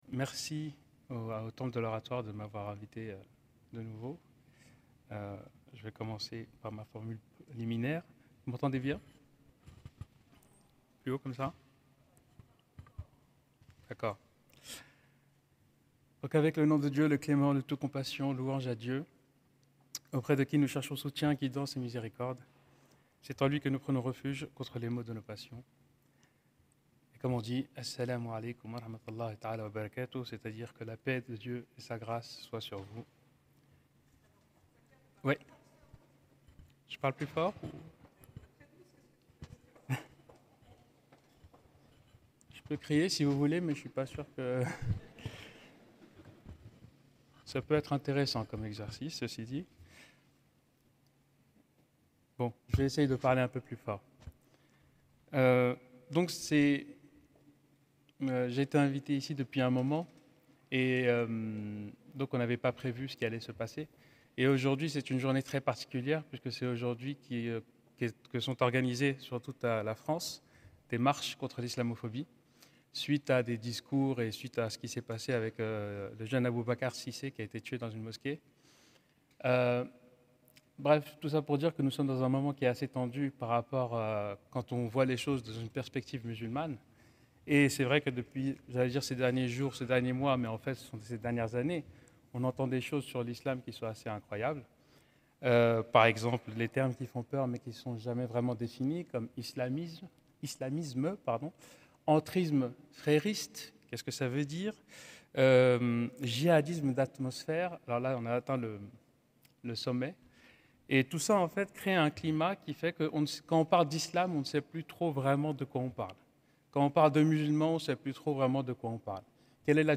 Culte interreligieux du 11 mai 2025 - Oratoire du Louvre